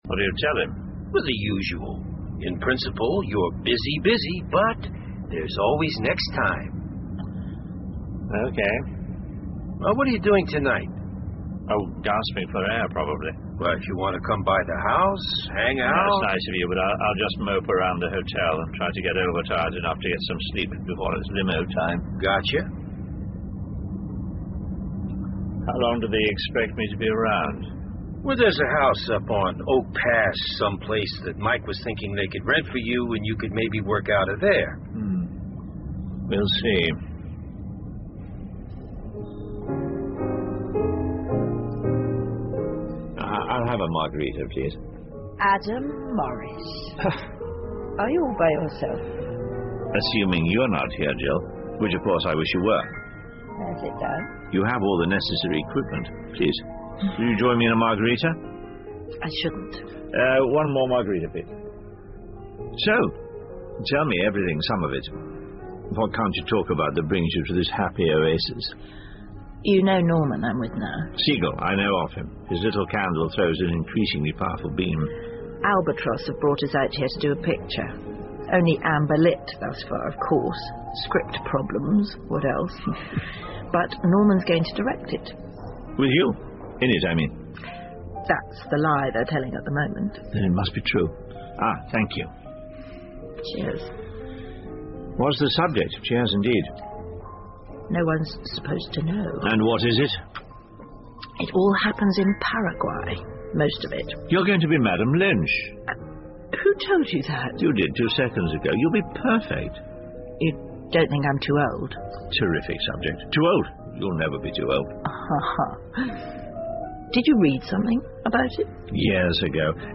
英文广播剧在线听 Fame and Fortune - 54 听力文件下载—在线英语听力室